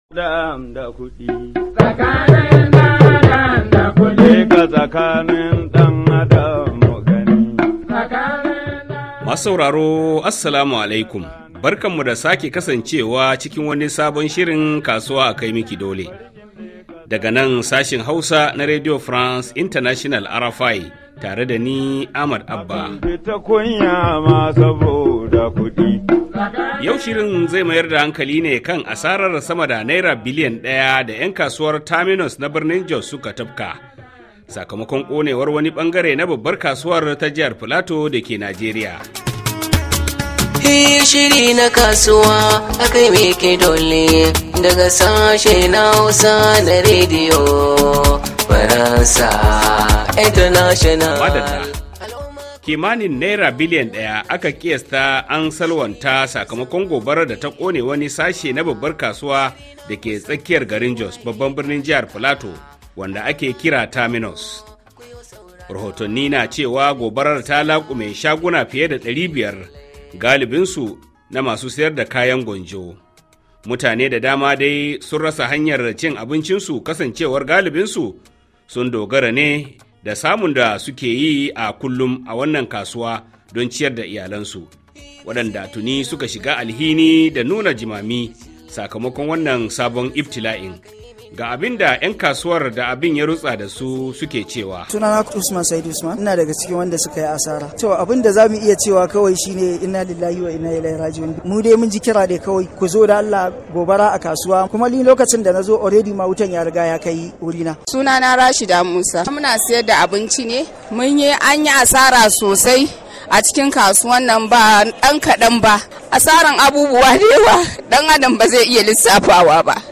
Tare da jin ta bakin 'Yan kasuwa da Masana’antu dangane da halin da suke ciki.